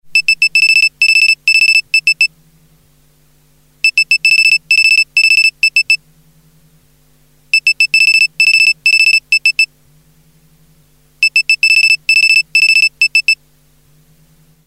sos-morse-code_24673.mp3